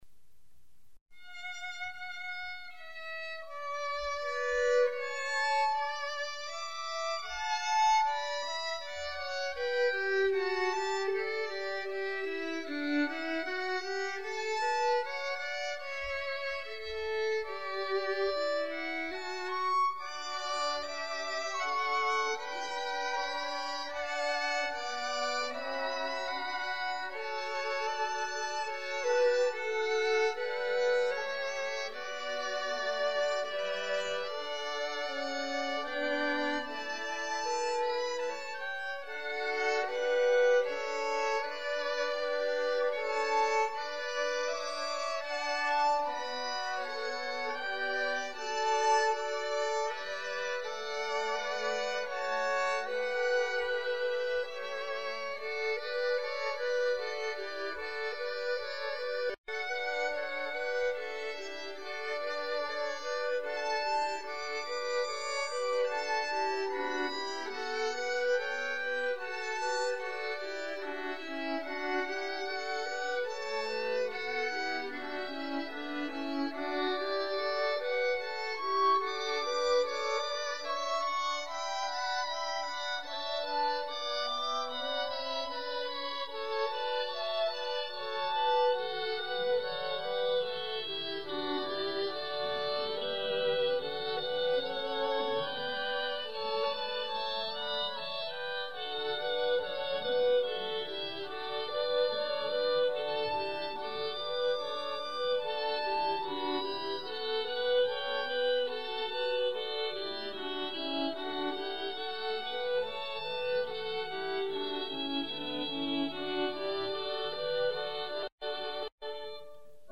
Christmas Eve Service — Audio Sermons — Brick Lane Community Church